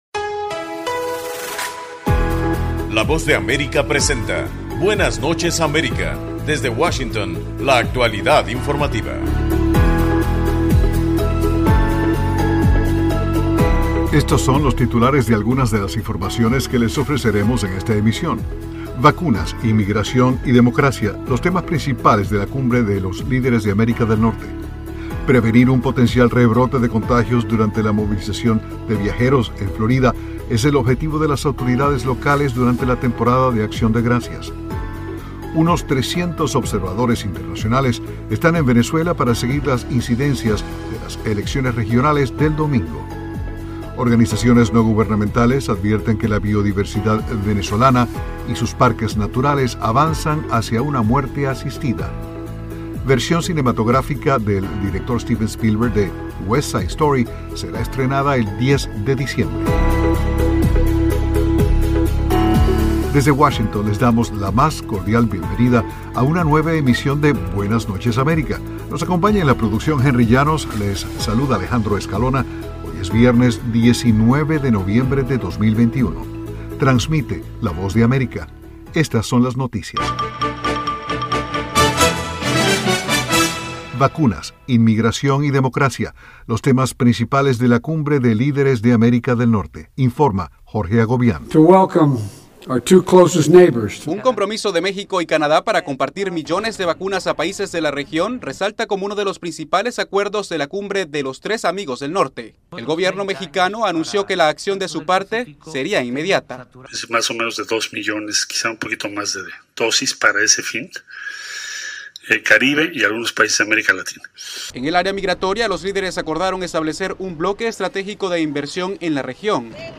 Programa informativo de la Voz de América, Buenas Noches América.